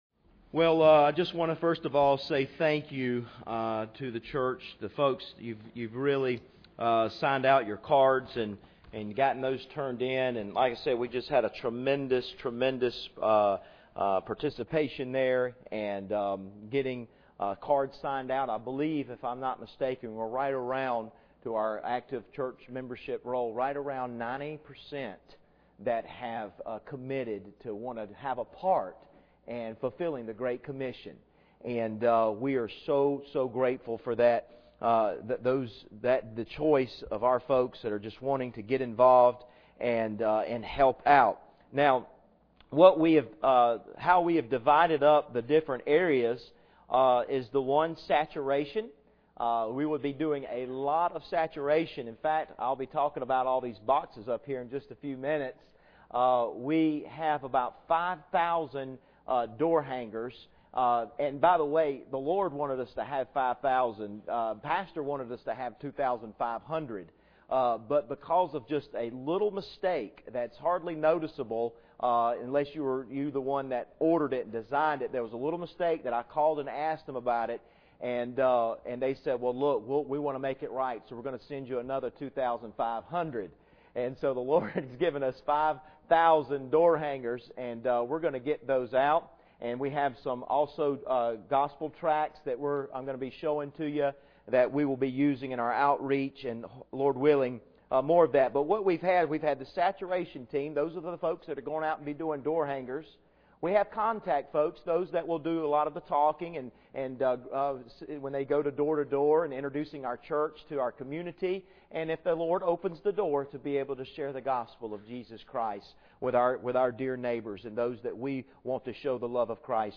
Passage: 1 Thessalonians 3:1-5 Service Type: Sunday Evening